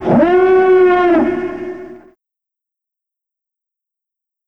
starwarsalarmdelay.wav